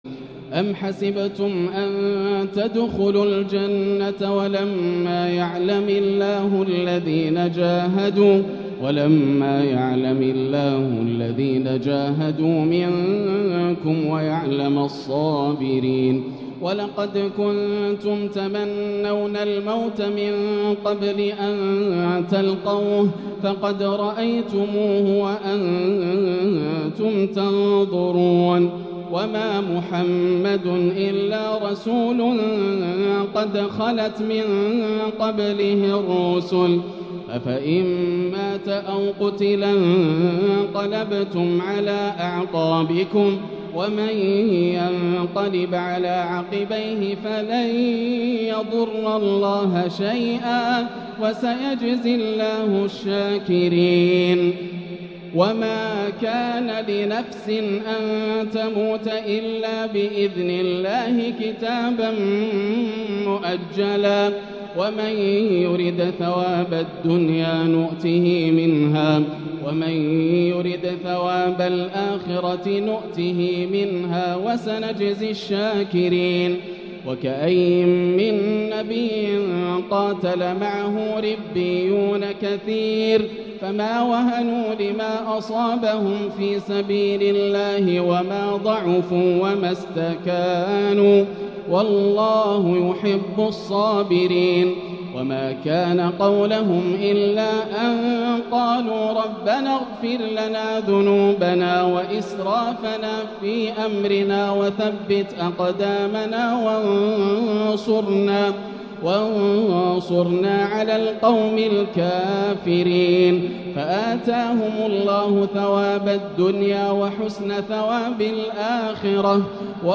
تلاوة رستية تفيضُ جمالًا وعذوبة > الروائع > رمضان 1445هـ > التراويح - تلاوات ياسر الدوسري